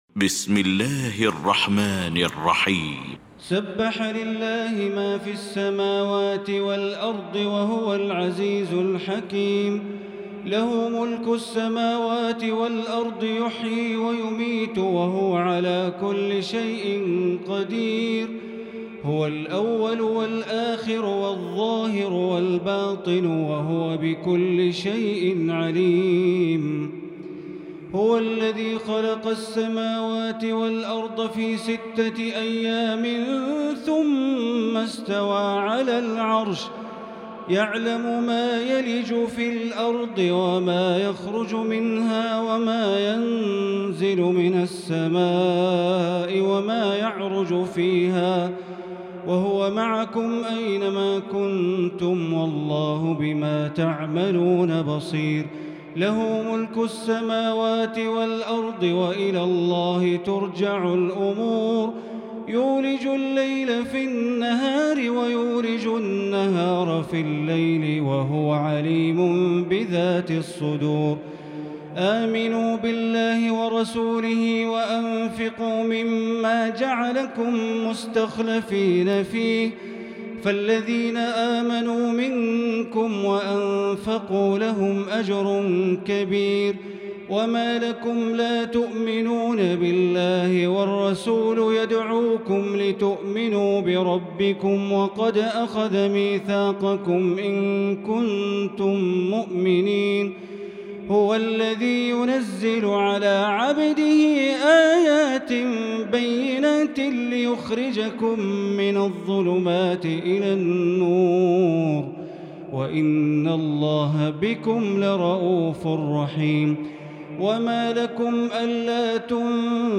المكان: المسجد الحرام الشيخ: معالي الشيخ أ.د. بندر بليلة معالي الشيخ أ.د. بندر بليلة الحديد